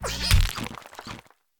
Cri de Forgerette dans Pokémon Écarlate et Violet.